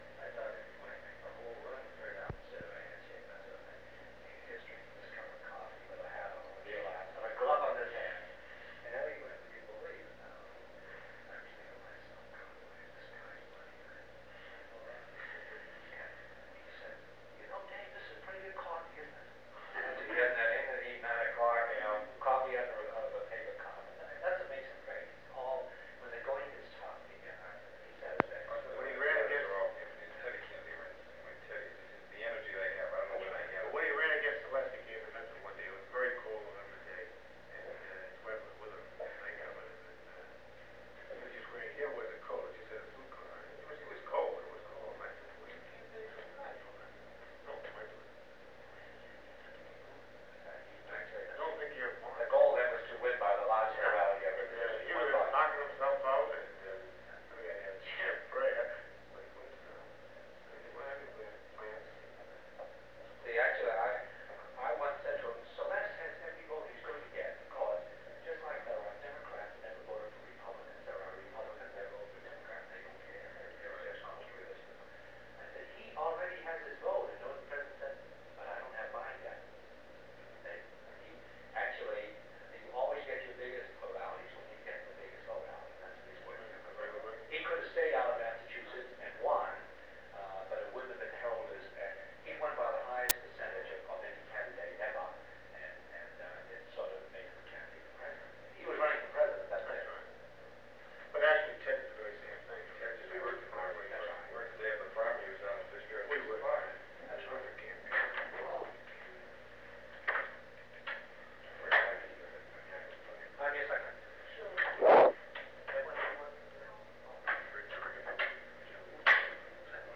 Informal Office Conversation
Secret White House Tapes | John F. Kennedy Presidency Informal Office Conversation Rewind 10 seconds Play/Pause Fast-forward 10 seconds 0:00 Download audio Previous Meetings: Tape 121/A57.